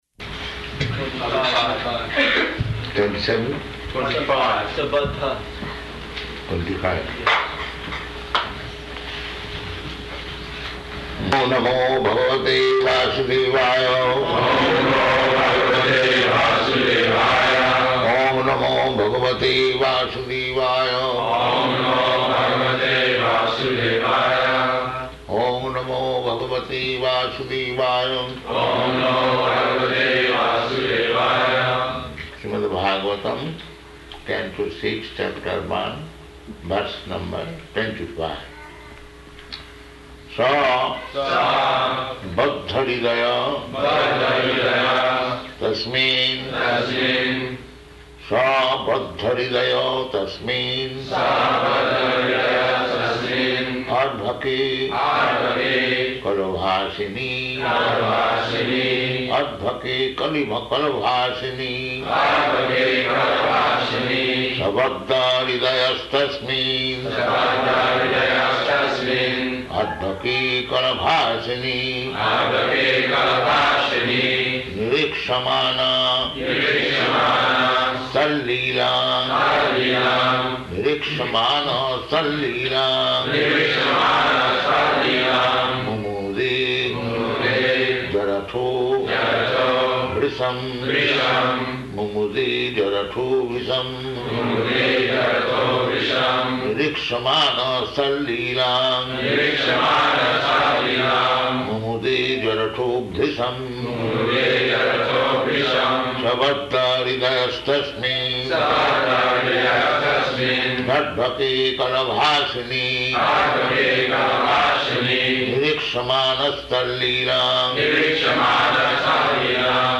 Śrīmad-Bhāgavatam 6.1.25 --:-- --:-- Type: Srimad-Bhagavatam Dated: May 25th 1976 Location: Honolulu Audio file: 760525SB.HON.mp3 Prabhupāda: Twenty-seven?
[leads devotees in chanting] sa baddha-hṛdayas tasminn arbhake kala-bhāṣiṇi nirīkṣamāṇas tal-līlāṁ mumude jaraṭho bhṛśam [ SB 6.1.25 ] [02:06] So the youngest child was very dear.